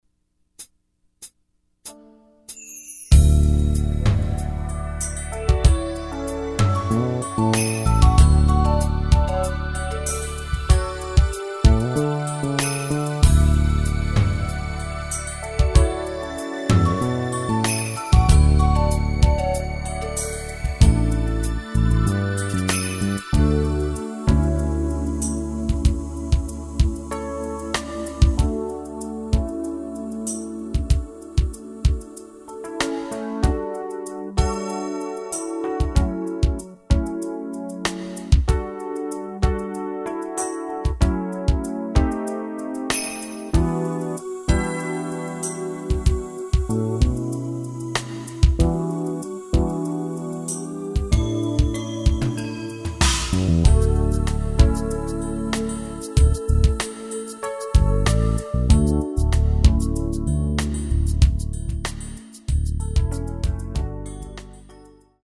Key of D